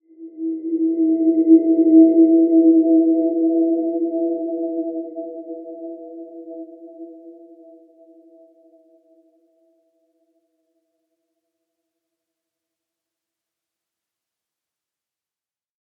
Dreamy-Fifths-E4-p.wav